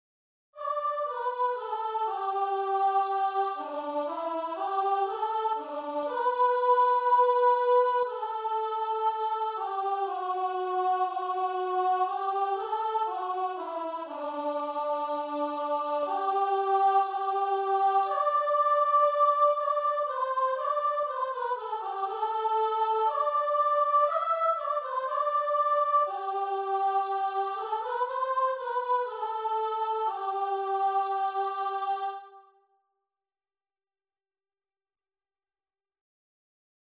Christian
4/4 (View more 4/4 Music)
Allegro (View more music marked Allegro)
Classical (View more Classical Guitar and Vocal Music)